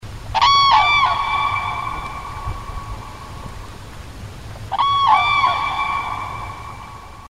Звуки журавлей
Вы можете слушать онлайн или скачать знаменитое курлыканье, крики и звуки общения этих грациозных птиц в формате mp3.